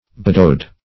badaud - definition of badaud - synonyms, pronunciation, spelling from Free Dictionary
Badaud \Ba`daud"\, n. [F.]